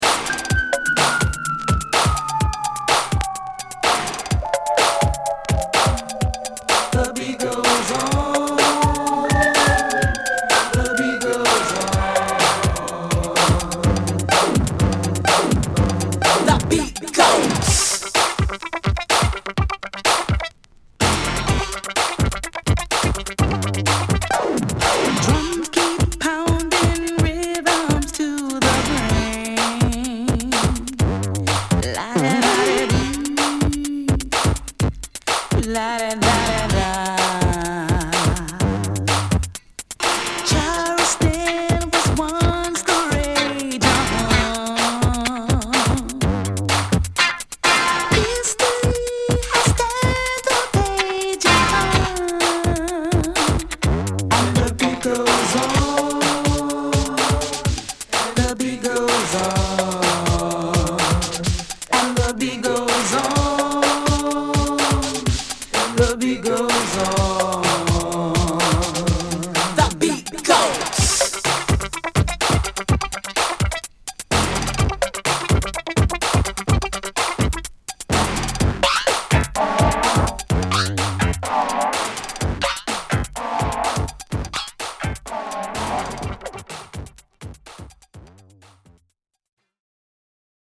Detroit産Electro Disco Classic!!